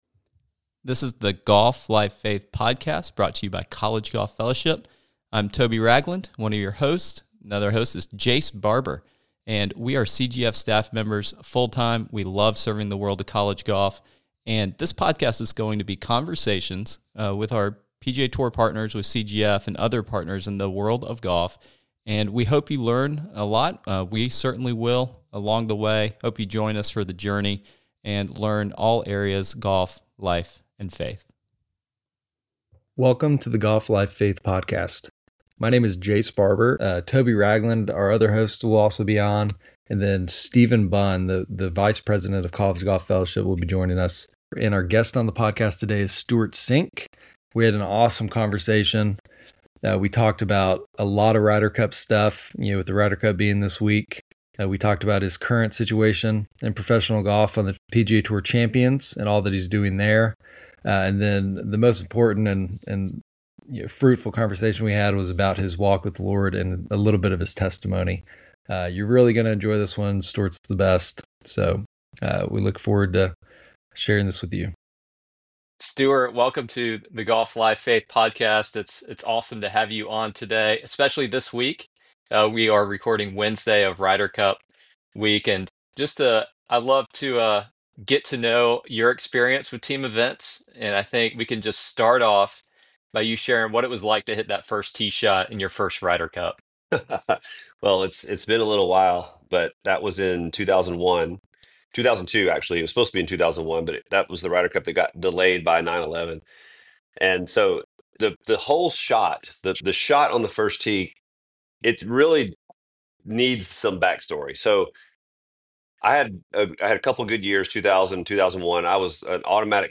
Dive into an engaging conversation about Stewart's experiences at the Ryder Cup, his journey on the PGA Tour Champions, and his profound faith journey.